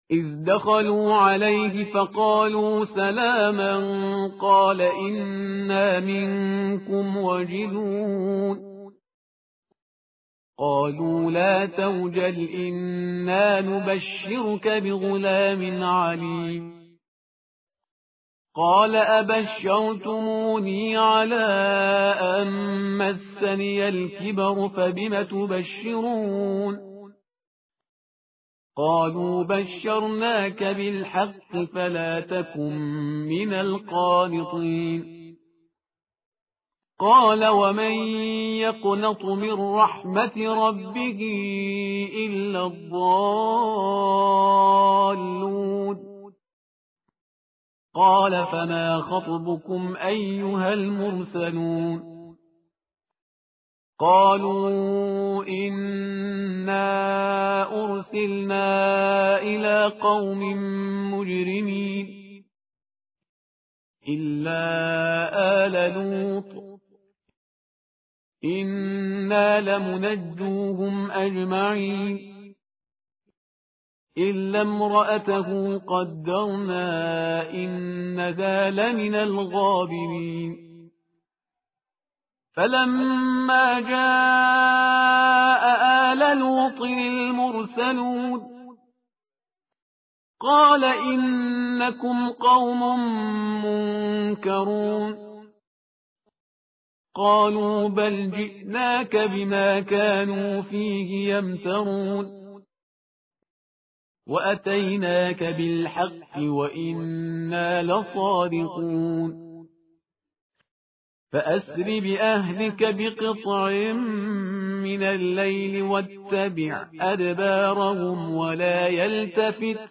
متن قرآن همراه باتلاوت قرآن و ترجمه
tartil_parhizgar_page_265.mp3